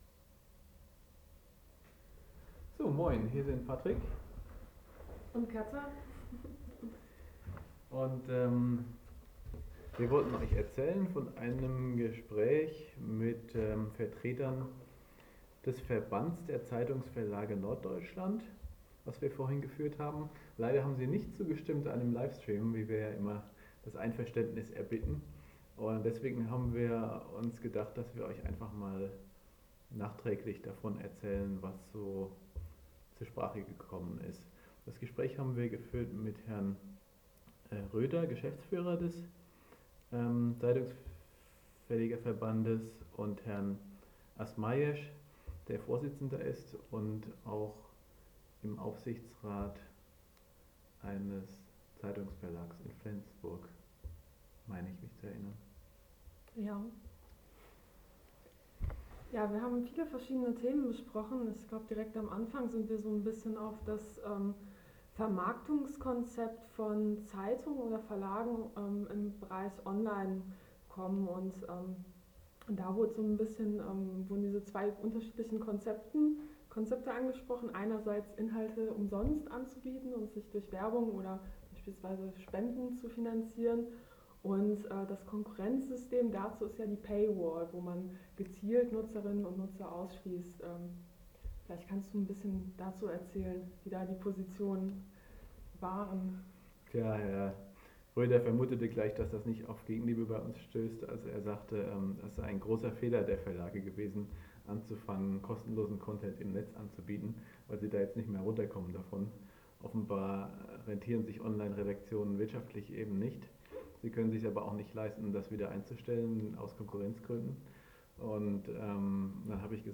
Bericht vom Treffen der Parlamentarischen Geschäftsführer
Patrick Breyer hat die Piraten beim Treffen der Parlamentarischen Geschäftsführer vertreten und erstattet der Fraktion Bericht: